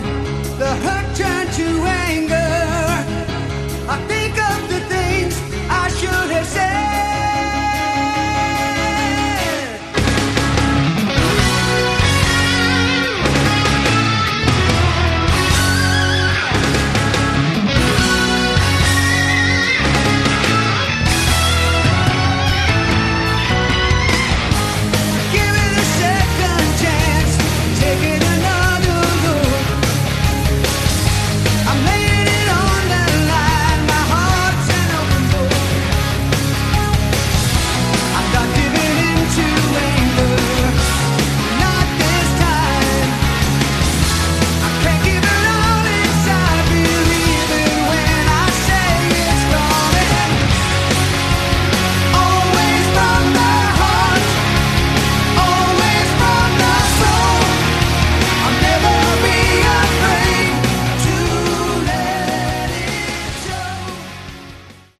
Category: AOR
guitar, bass, keyboards
drums